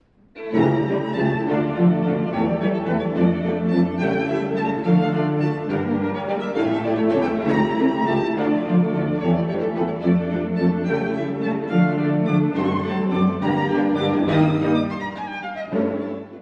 この曲は、弦楽六重奏曲ではあるが、響きがとても豊かで交響的な印象を受けます。
↑古い録音のため聴きづらいかもしれません！（以下同様）
豪華絢爛な楽章です。
流れるように、絶え間なく動きつづけるハーモニー。
その上に乗って、情熱的な旋律が歌われます。